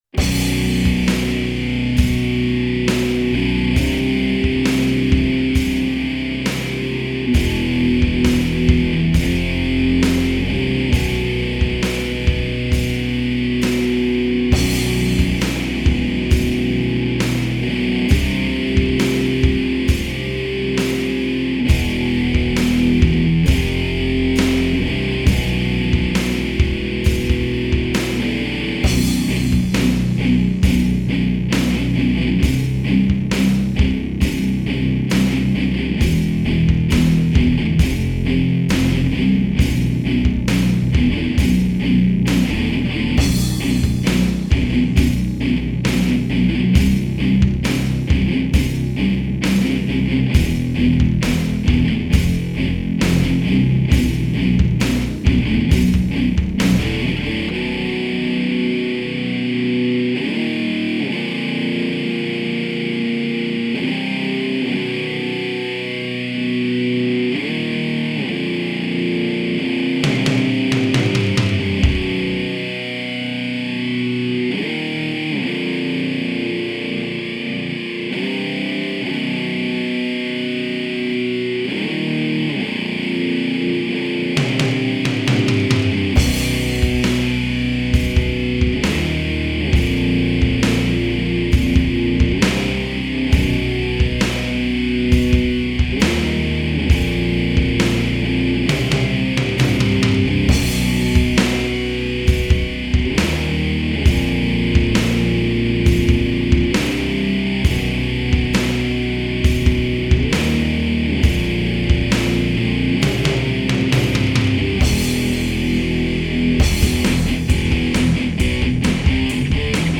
11:47:34  Darkthronowy pathos wraca do łask ?